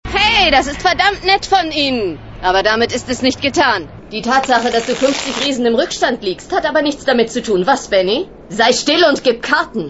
- Batman of the Future                    (S2E07     1999)  Lula [Stimme]